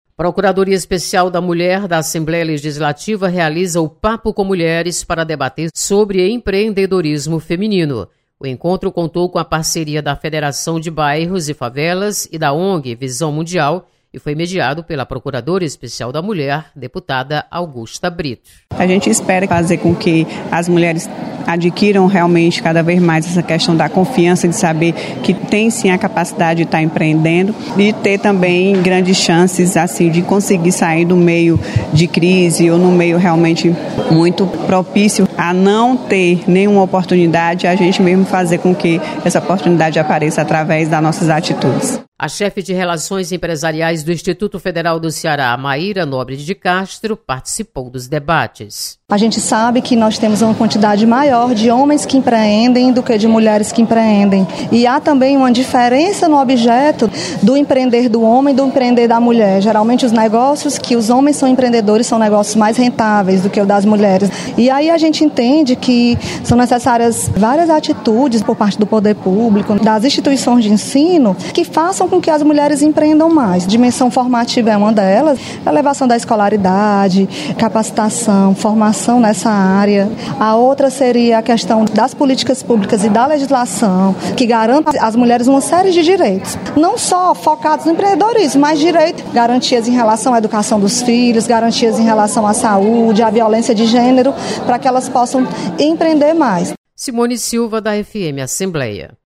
Publicado em Notícias